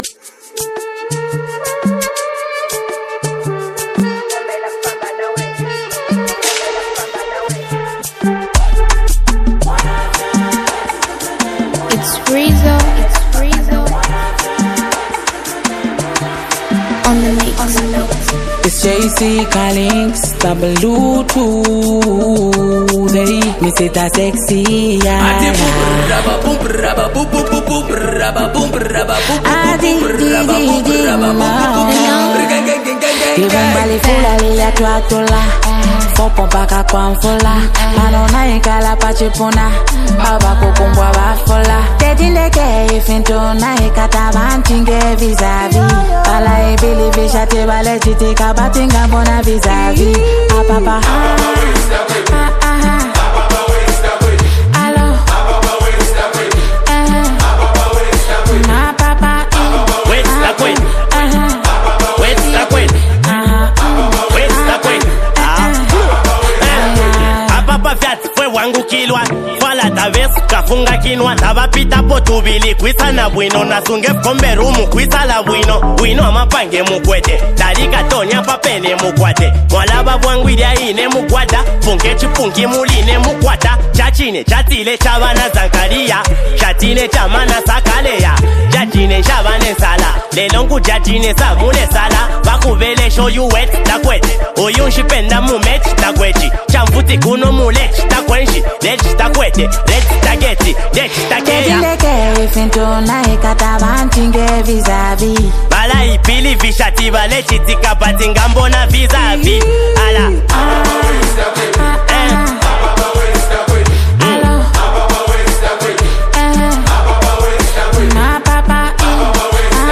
Zambian Mp3 Music
buzzing street anthem
Zambian rap sensation artist.